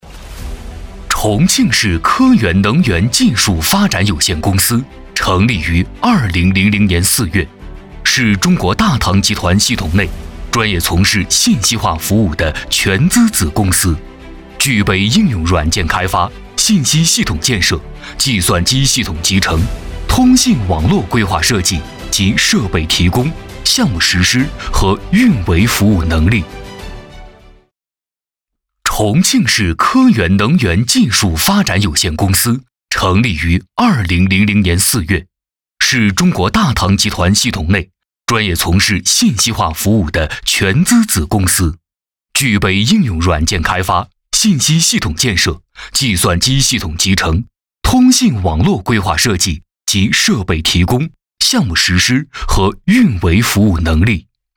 男16号配音师
声音磁性细腻、音域跨度大，擅长广告、专题、纪录等影片的配音。
专题片-男16-庆市科源能源技术.mp3